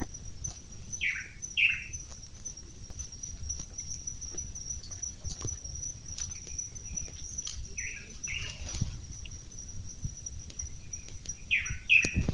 Tangará (Chiroxiphia caudata)
Nome em Inglês: Blue Manakin
Localidade ou área protegida: Parque Provincial Teyú Cuaré
Condição: Selvagem
Certeza: Observado, Gravado Vocal